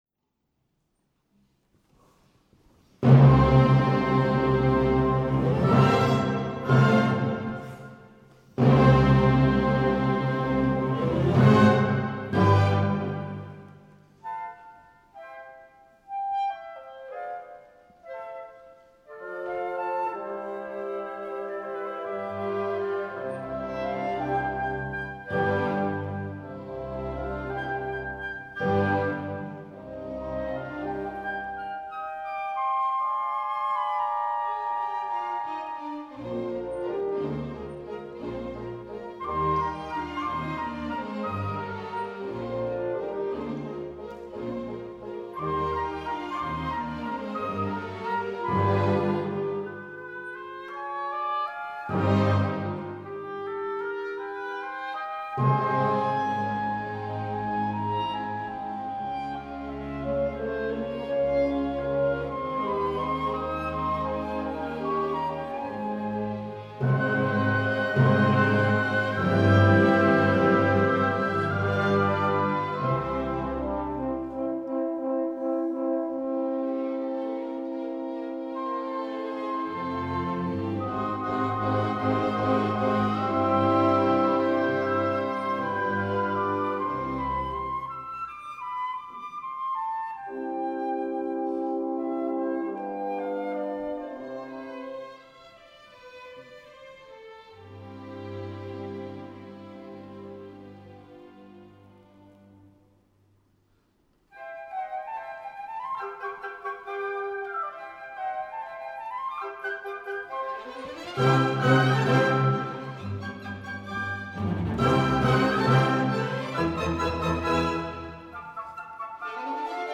F. Schubert: Sinfonía nº 6 en Do Mayor, D. 589